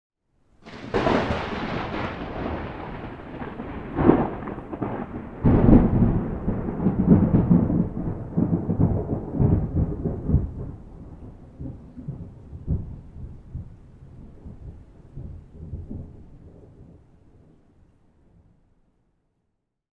thunder_21.ogg